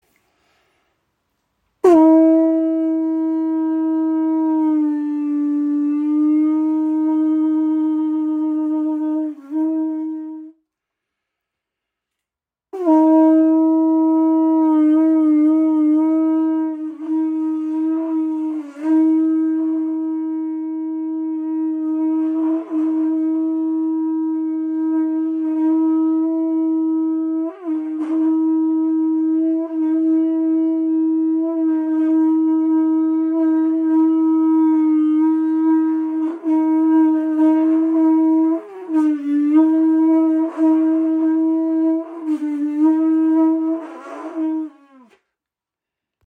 Muschelhorn | Pūtātara | Shankha | Conch Shell | Kavadi | Conque | ca. 24 cm
Lässt sich mit Zirkularatmung lange spielen.